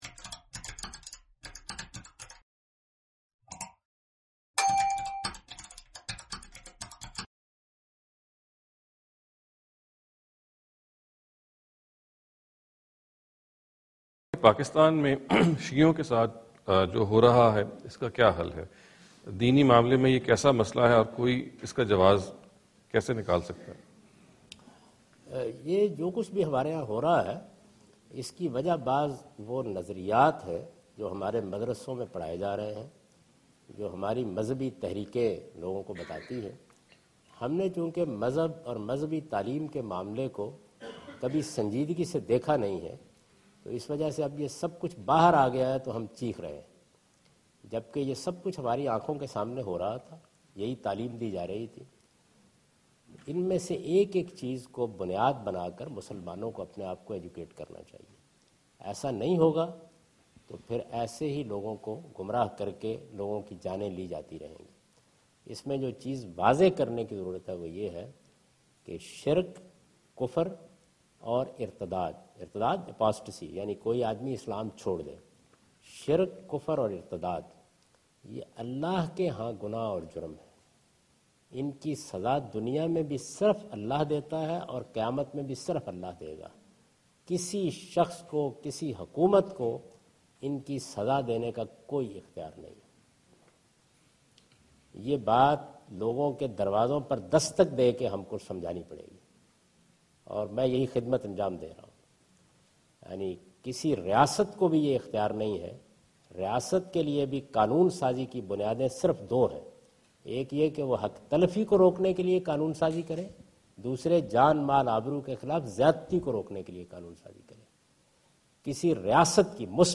Javed Ahmad Ghamidi answer the question about "Causes of Discrimination against Shia in Pakistan" during his visit to Georgetown (Washington, D.C. USA) May 2015.